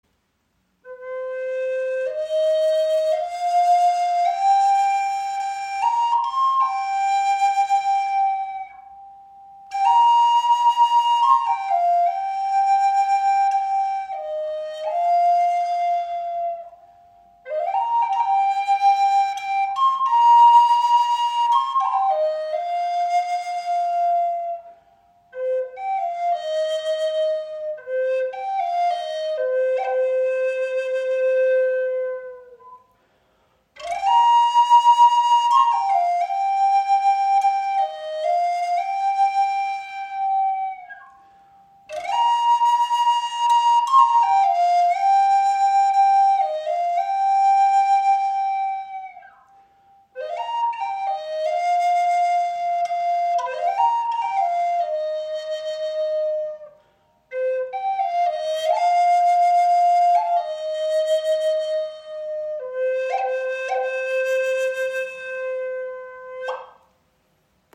• Icon Gesamtlänge 43 cm, 19 mm Innenbohrung – leichter, erhebender Klang
Handgefertigte Yellow-Bird-Flöte in hohem C-Moll aus pazifischer Eibe und Canary Wood. Klarer, frischer Klang für Vogelrufe, Meditation und freies Spiel.
In hohem C-Moll gestimmt erklingt sie mit einer süssen, klaren und frischen Stimme, ideal für Vogelrufe, Zwitschern, feine Verzierungen und rhythmische Melodien. Trotz ihrer kleinen Grösse besitzt sie überraschend viel Volumen und Durchsetzungskraft.